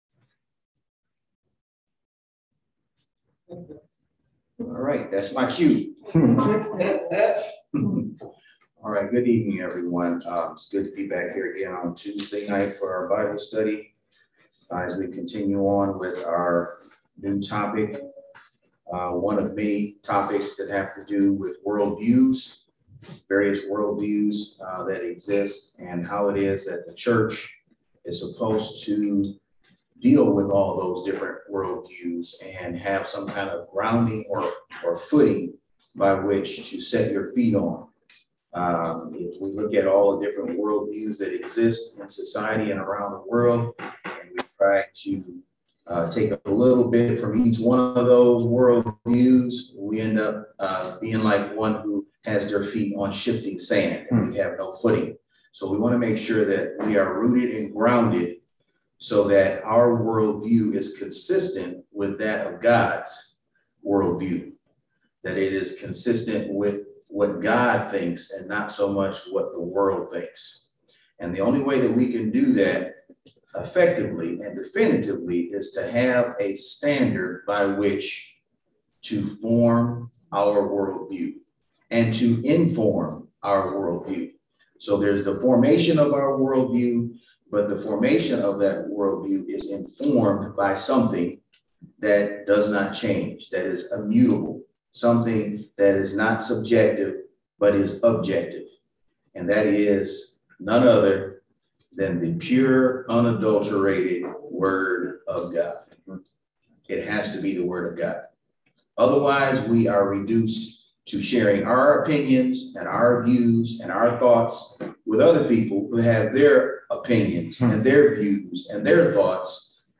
Bible Study - New Life Community Church